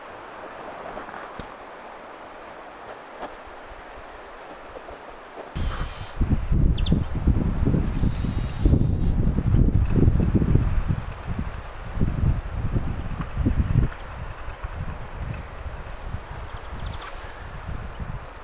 kuvien punakylkirastaan siritysääniä
kuvien_punakylkirastaan_siritysaania.mp3